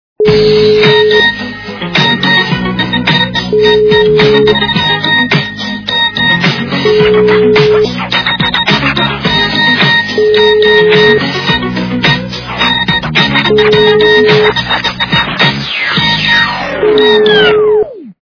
звуки для СМС